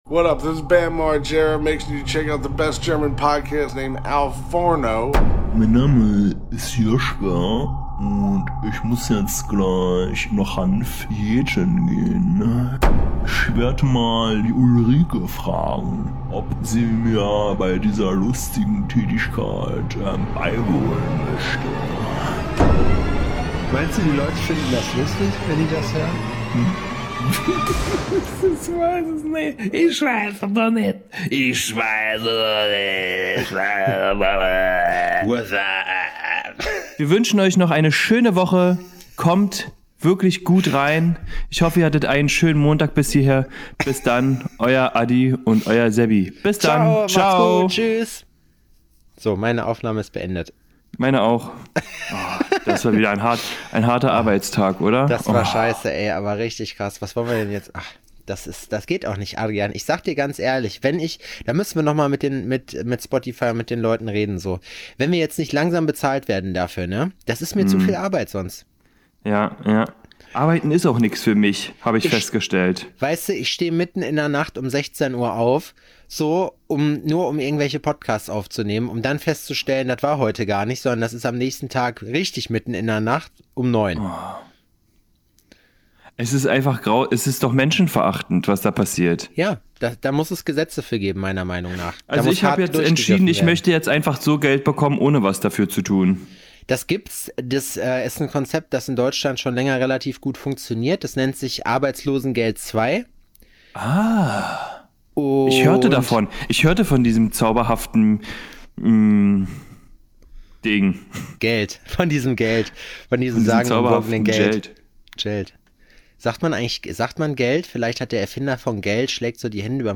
Lockerer Frühstückstalk mit euren beiden Helden